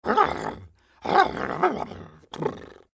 audio: Converted sound effects
AV_horse_long.ogg